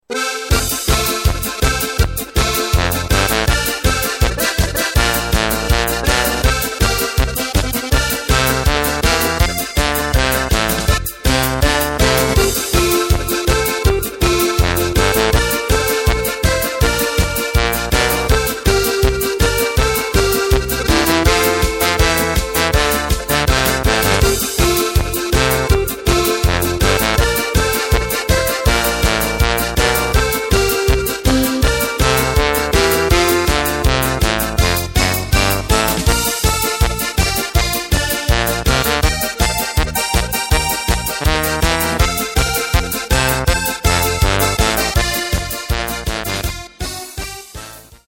Takt:          2/4
Tempo:         120.00
Tonart:            F
Flotte Polka aus dem Jahr 2013!